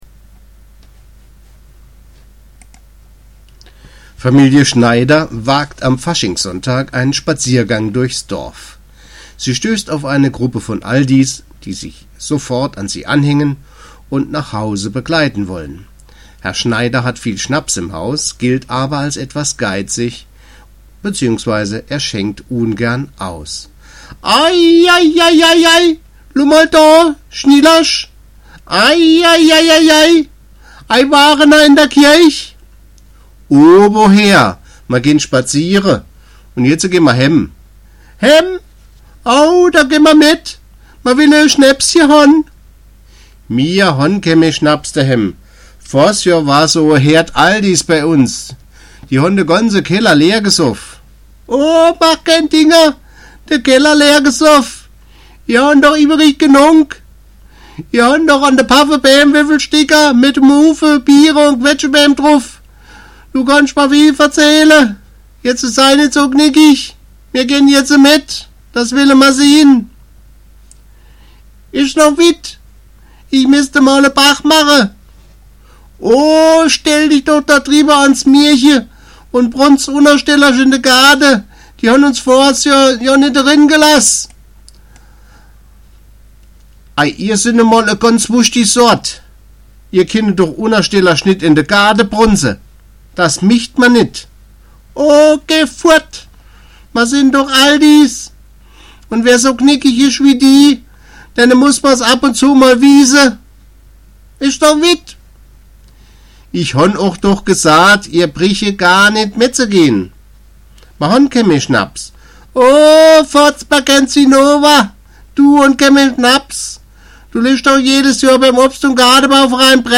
Aldi-Dialoge